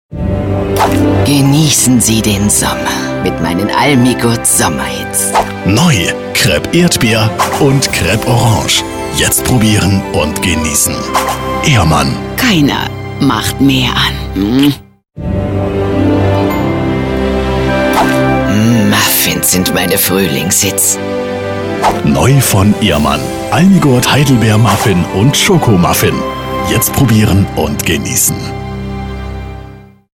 Synchronsprecher, Werbesprecher. Tiefe, maskuline Stimme. Sehr variabel, von seriös über freundlich bis angsteinflößend
Sprechprobe: eLearning (Muttersprache):